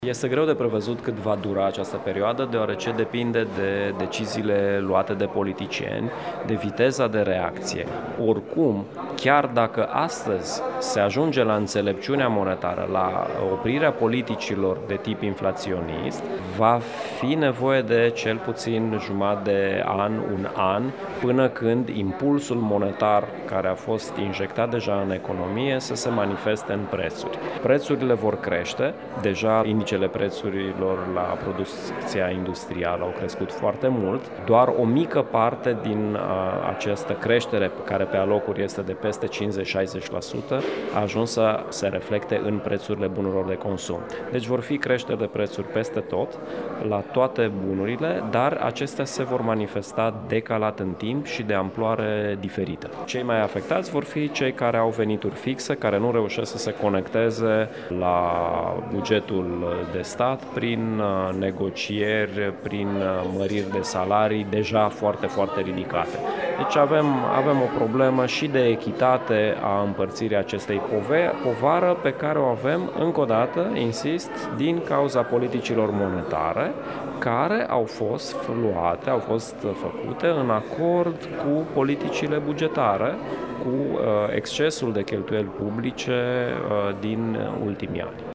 Aseară, la Camera de Comerț Iași s-a desfășurat conferința „Economia în vremea inflației”.